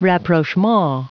Prononciation du mot rapprochement en anglais (fichier audio)
rapprochement.wav